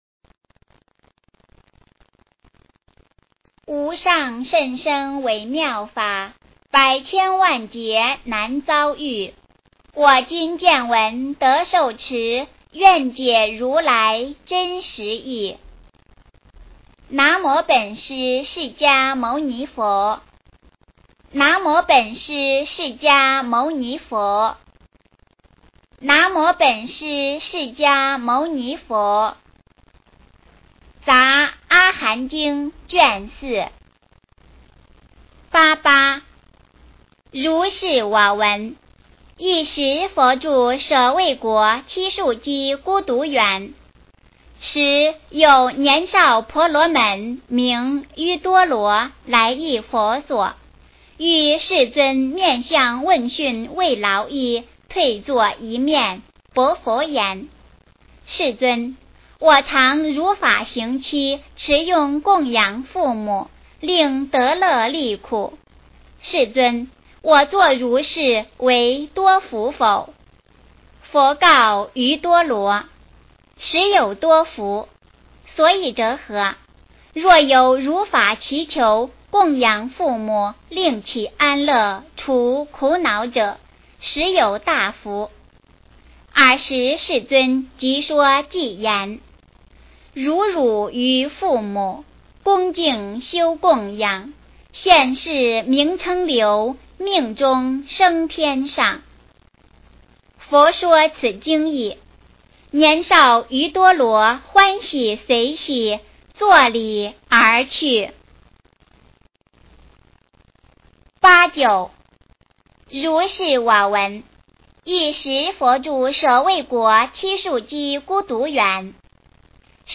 杂阿含经卷四 - 诵经 - 云佛论坛